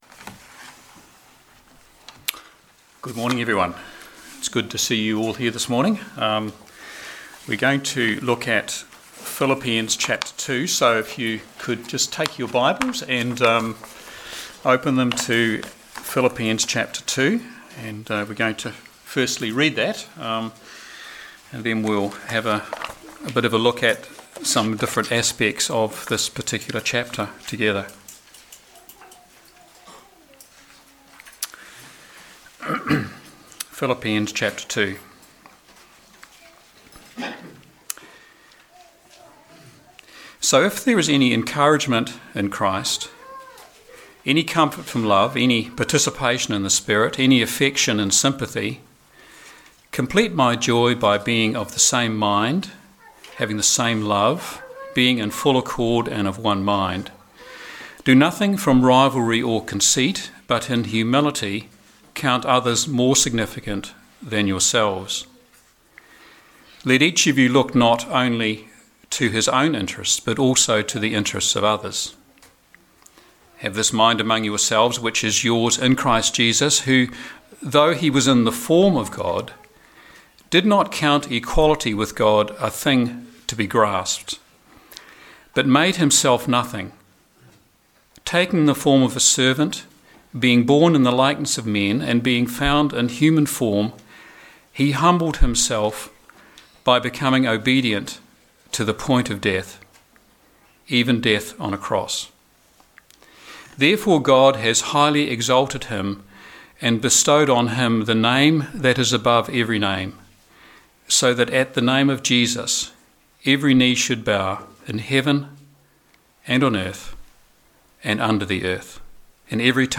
From Series: “Standalone Sermons“